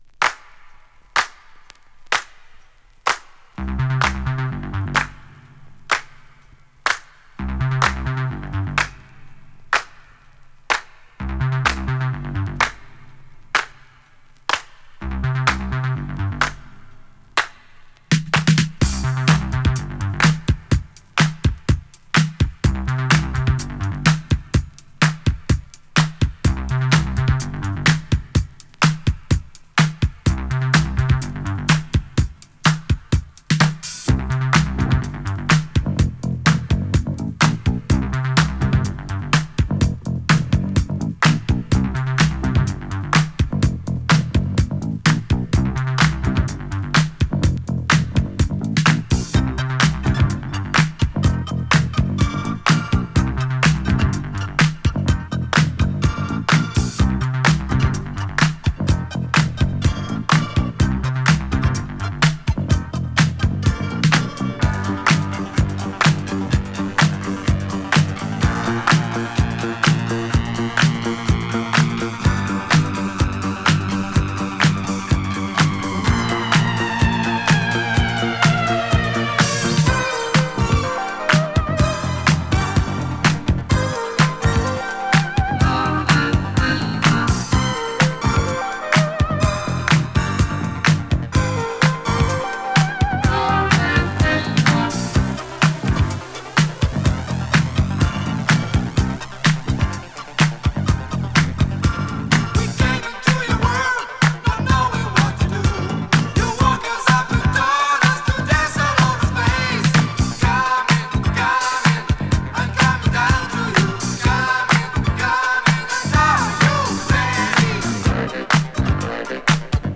キレの良いビートにシンセやギターにSEも飛び交うイタロディスコチューン